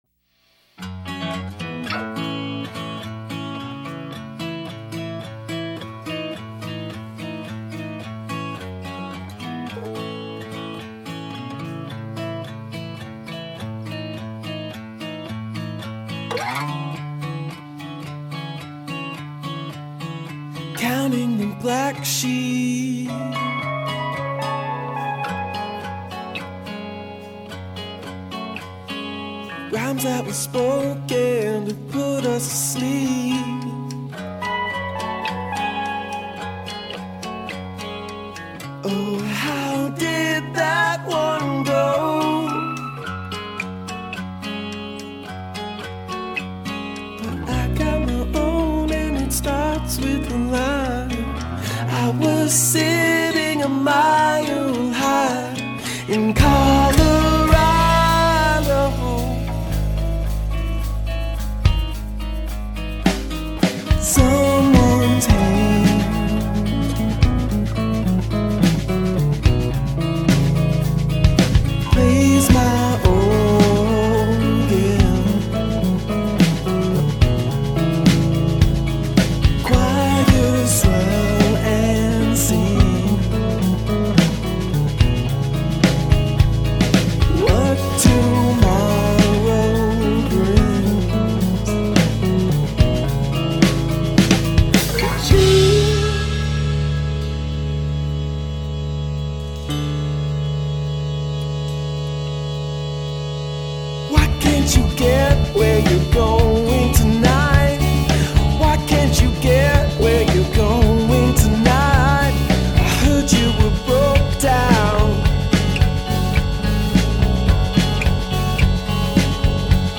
The Nashville trio went to Charleston